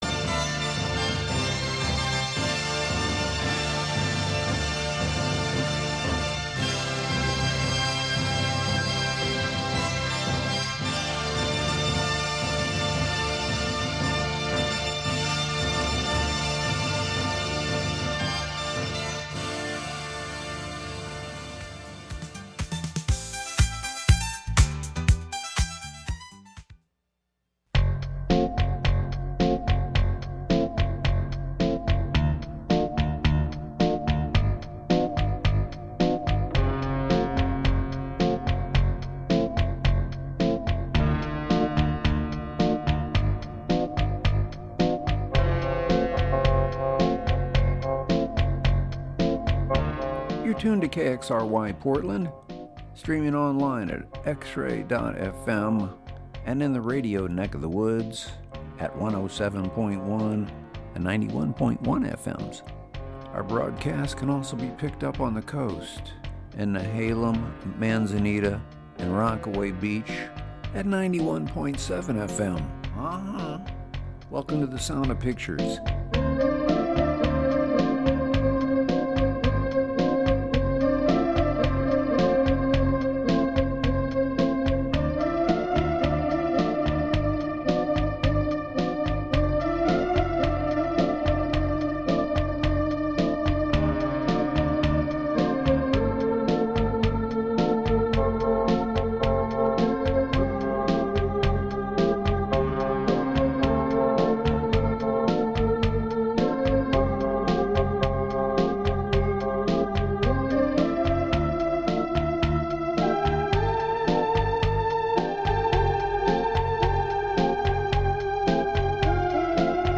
This show sets an imaginary stage for film music near and far. You’ll hear lots of soundtracks, source music and interviews with film composers with your cinema-centric host.